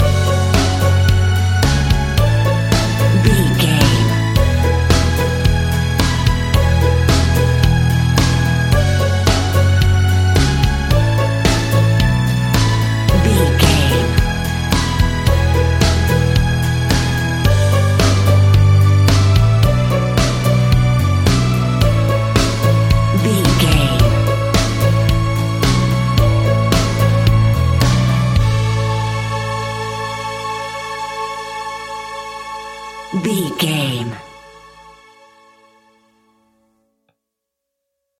Ionian/Major
calm
melancholic
soft
uplifting
electric guitar
bass guitar
drums
strings
pop rock
indie pop
instrumentals